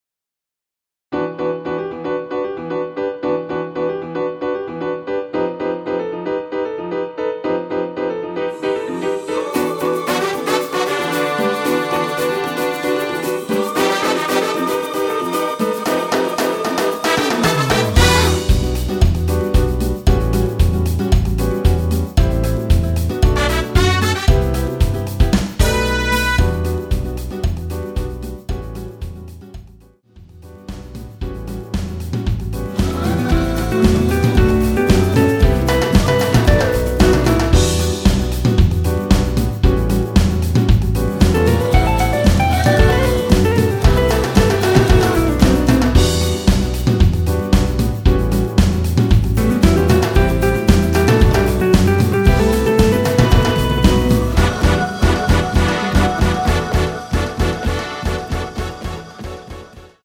전주, 간주, 엔딩 부분의 우~ 코러스가 들어가 있습니다.(미리듣기 참조)
Em
앞부분30초, 뒷부분30초씩 편집해서 올려 드리고 있습니다.
중간에 음이 끈어지고 다시 나오는 이유는